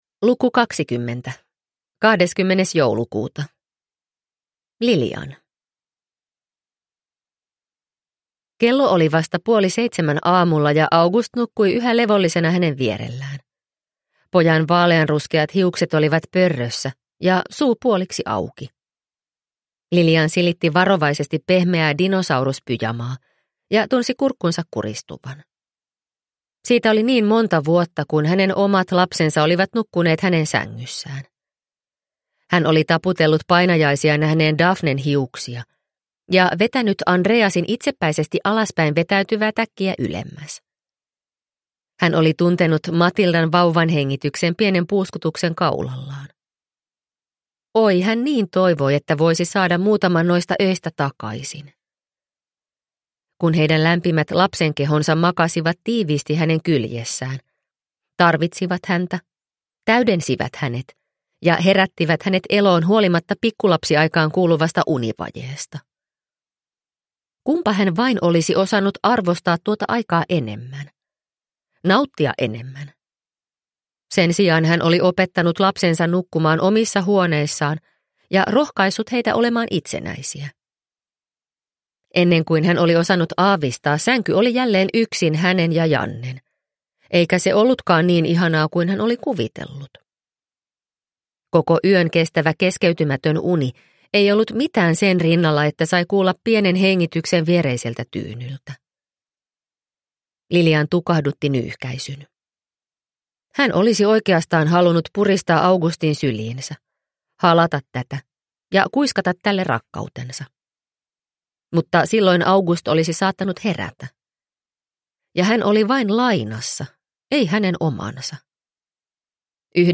Sankt Annan joulu – Ljudbok – Laddas ner